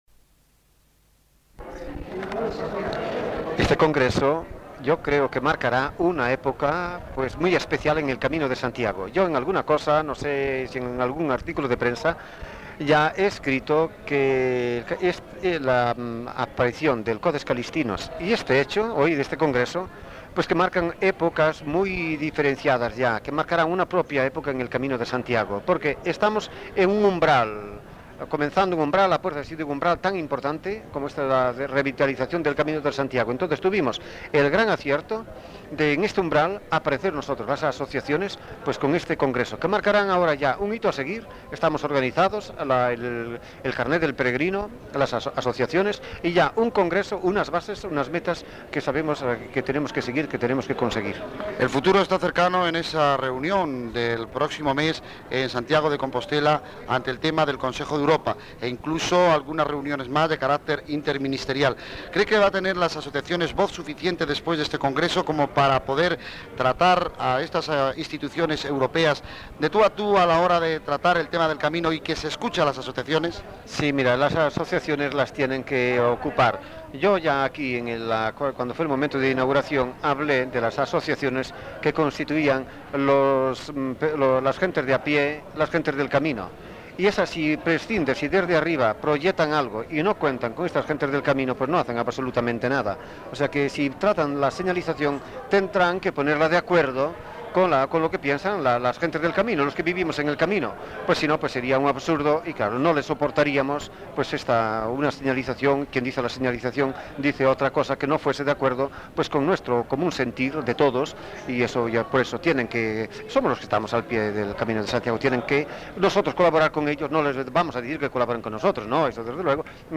I Congreso Internacional de Jaca.
Entrevista